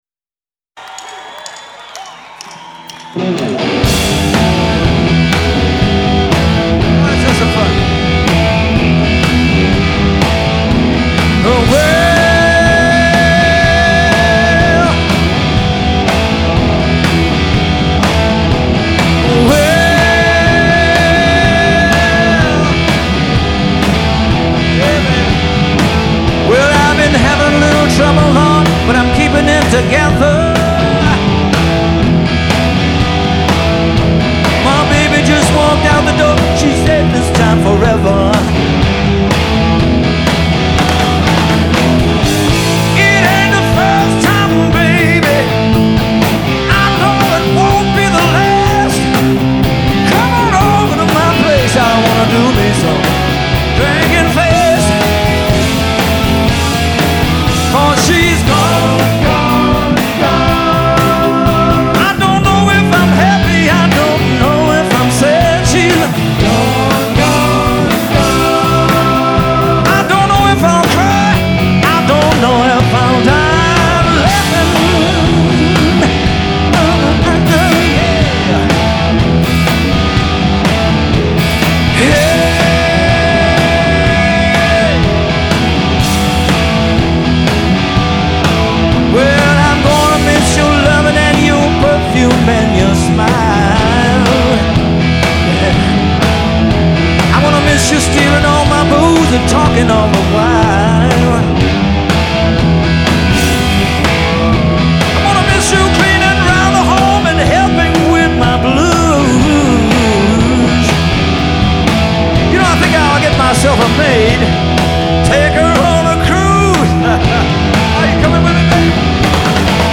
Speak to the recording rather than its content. straight off the stage